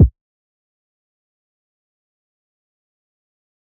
SizzKick4.wav